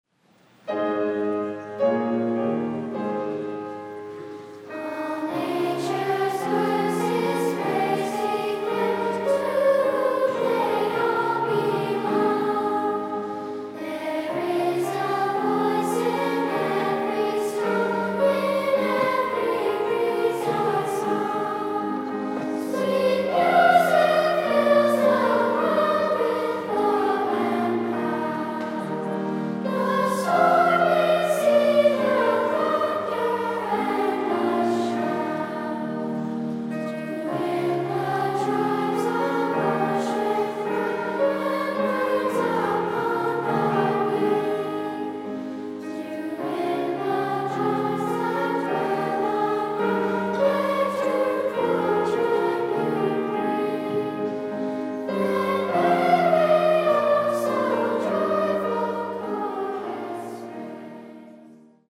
SA